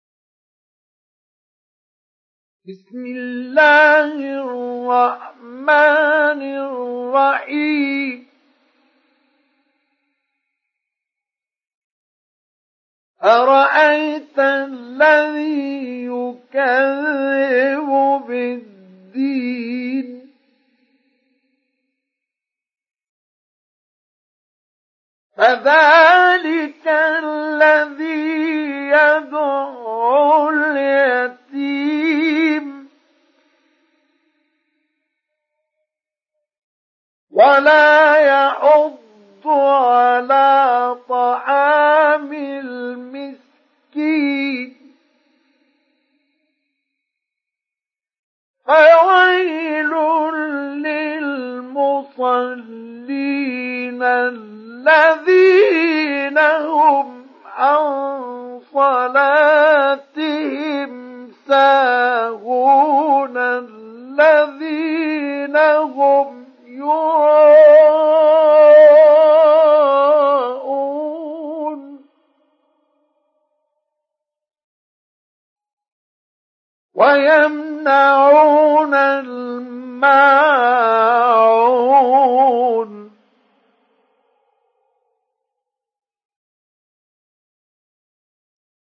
سُورَةُ المَاعُونِ بصوت الشيخ مصطفى اسماعيل